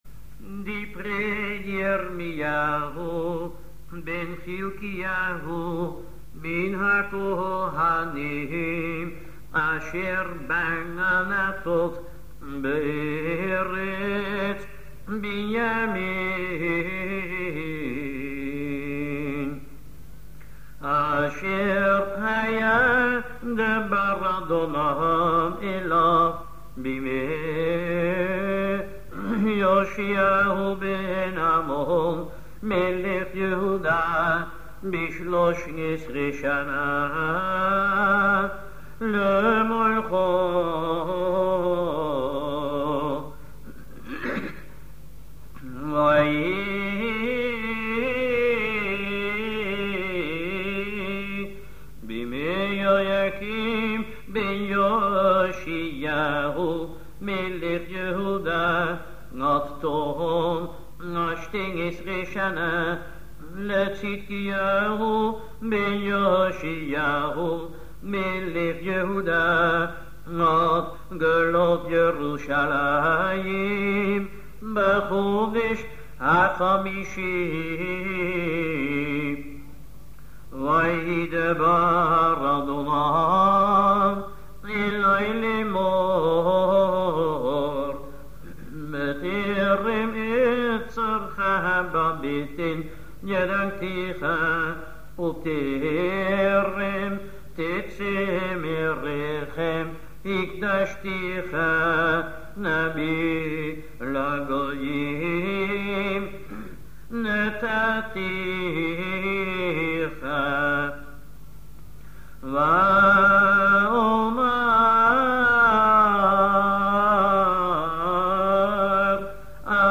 The Haftarot in the three weeks between 17 Tamuz  and 9 Av have a special melody: